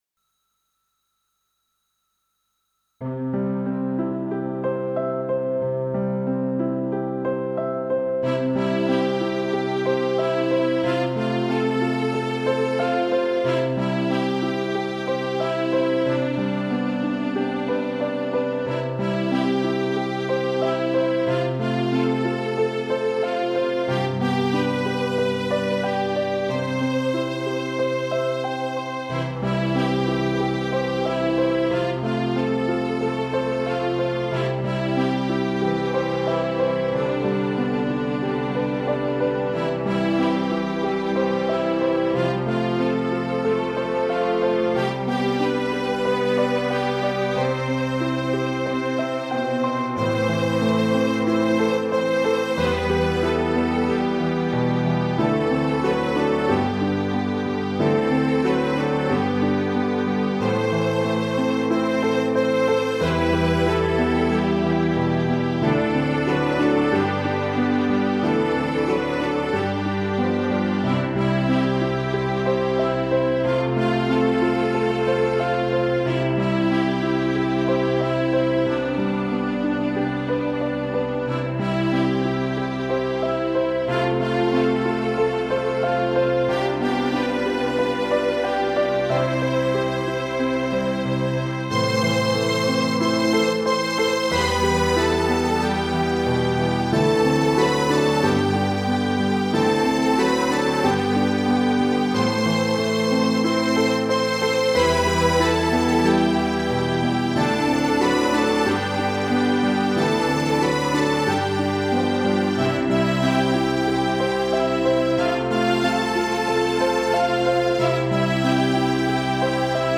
The backing tracks are here